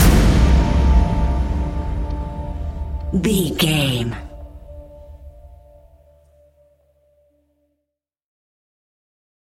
Fast paced
In-crescendo
Ionian/Major
D
dark ambient
EBM
synths